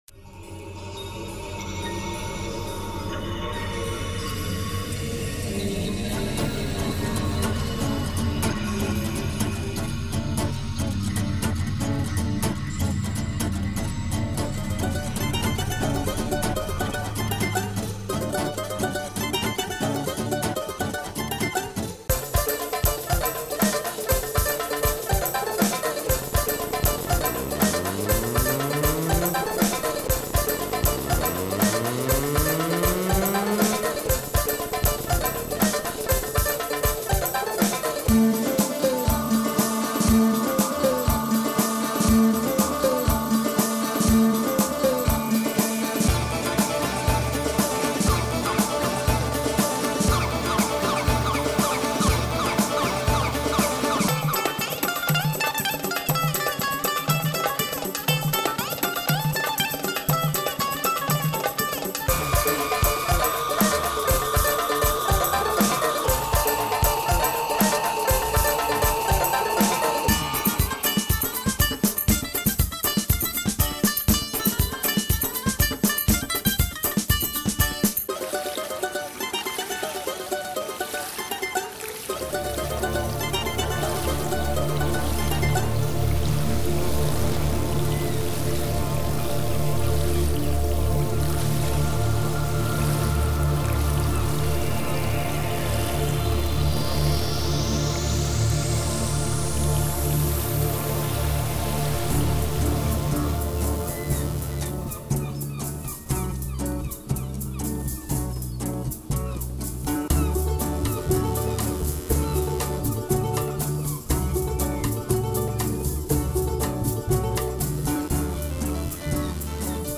Composition 3 : Cap au Sud : alors voyons voir le sud : et bien quel voyage là aussi !! je pourrais pas donné les cooordonnés GPS du parcours musical mais semblerait qu'il y ai eu une panne au km 586 , le mécano à l'air particulièrement compétent ^^ tout comme le musicien derrière cette piste .. excelent le final très positif !